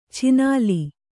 ♪ chināli